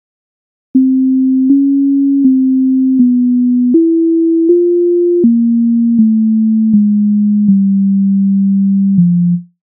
MIDI файл завантажено в тональності f-moll